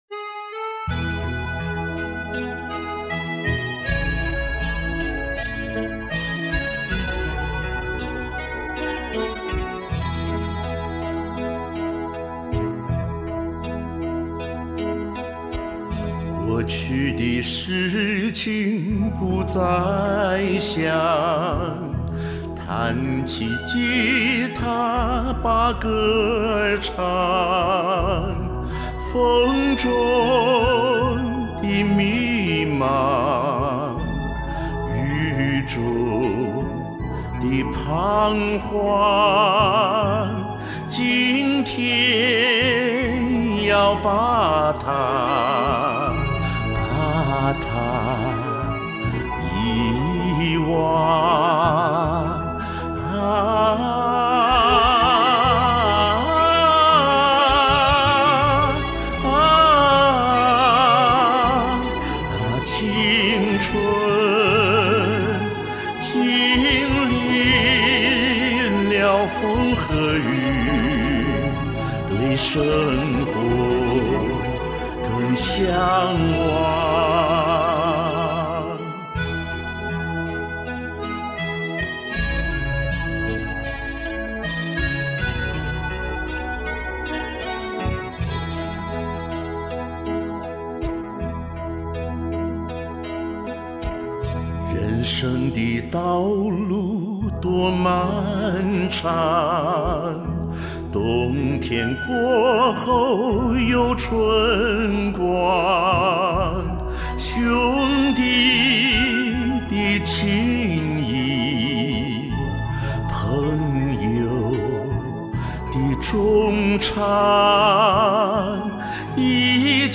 怀旧感人的歌声，太有共鸣了。。。